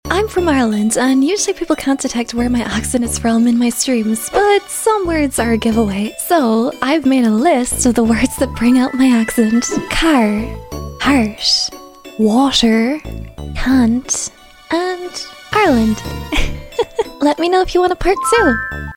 Words that bring out my sound effects free download